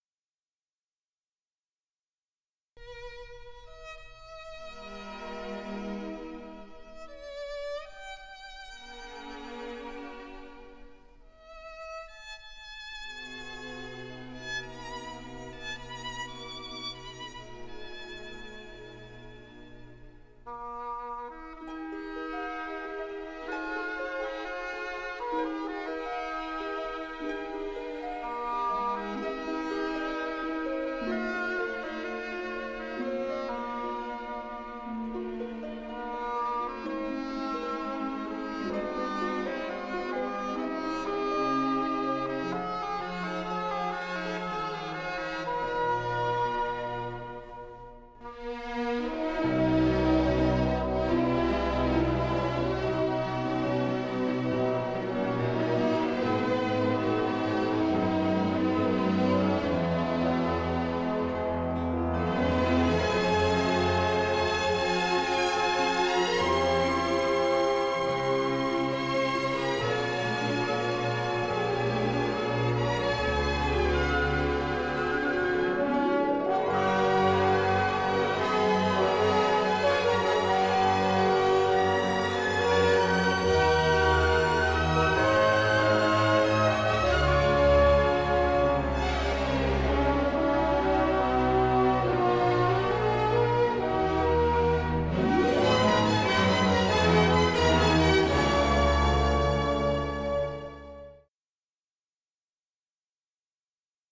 Narrateur : Une explosion